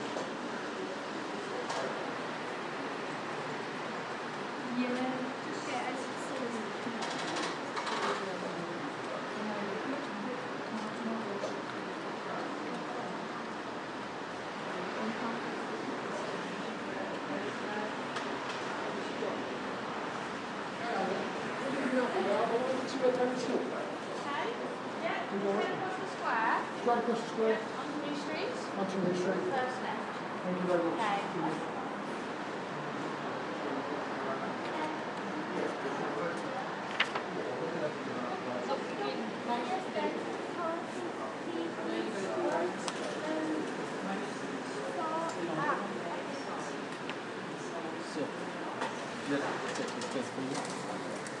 Ambience: Library reception
Birmingham Central Library